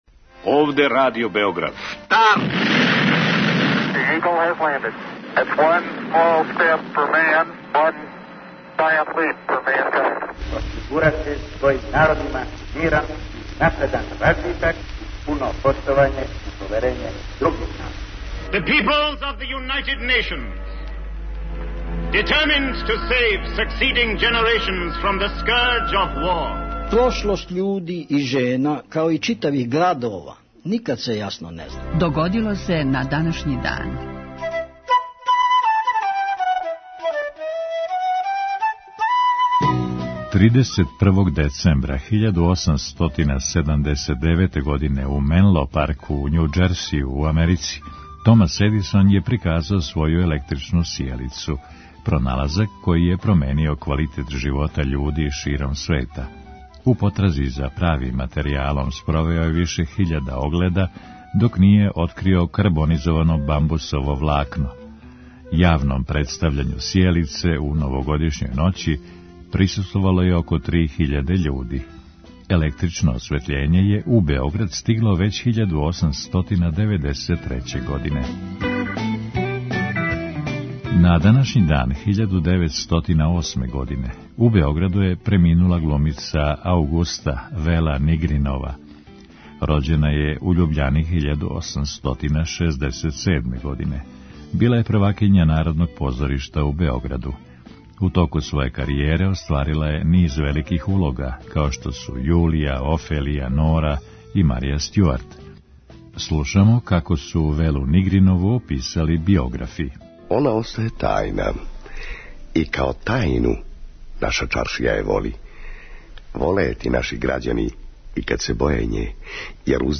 У петотоминутном прегледу, враћамо се у прошлост и слушамо гласове људи из других епоха.